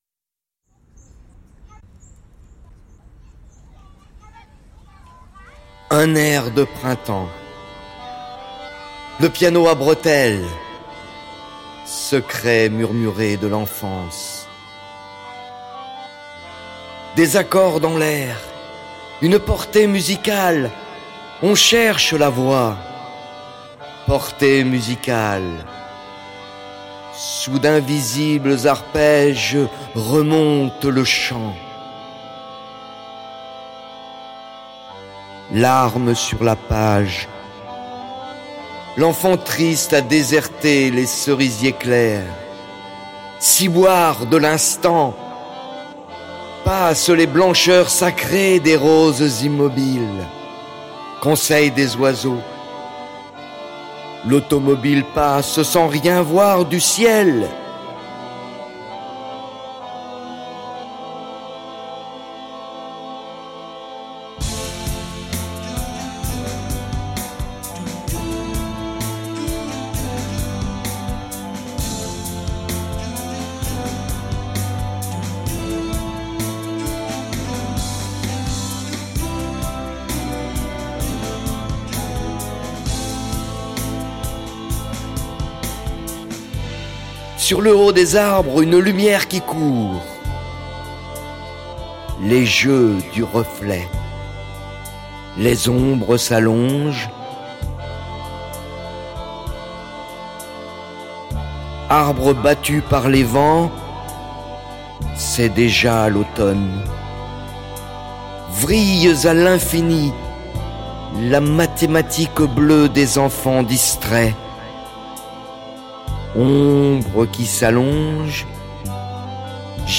Diction